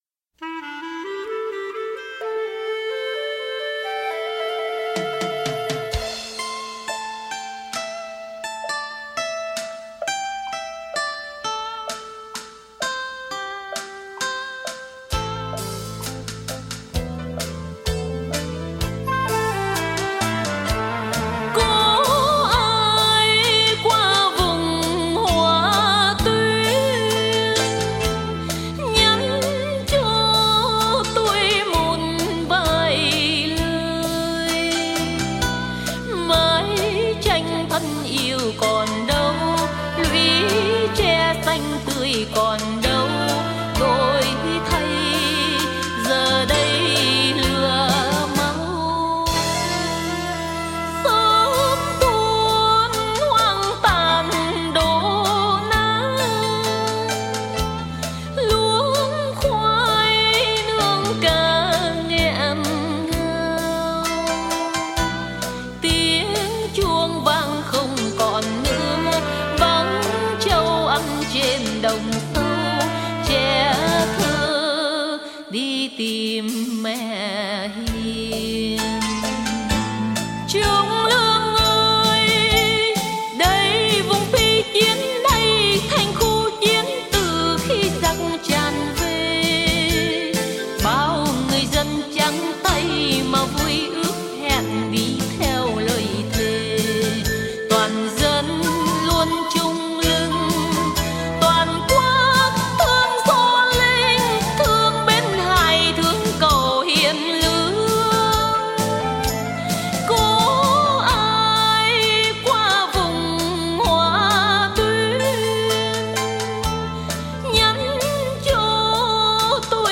đơn ca